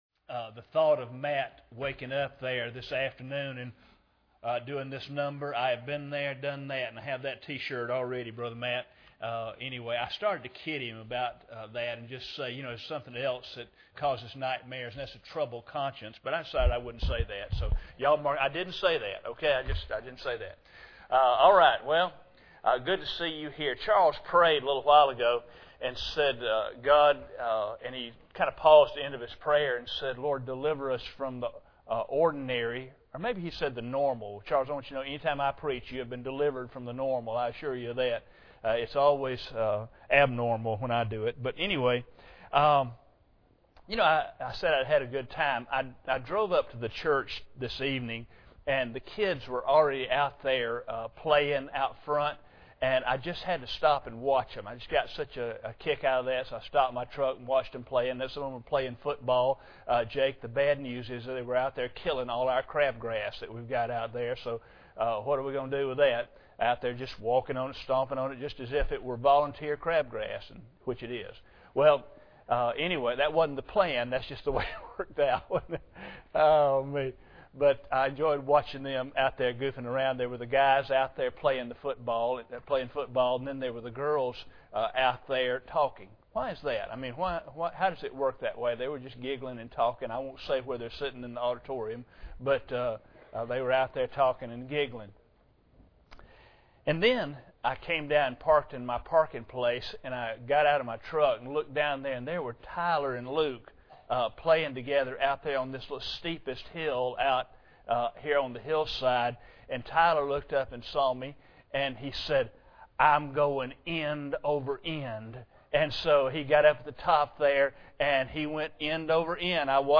Mark 16:15 Service Type: Sunday Evening Bible Text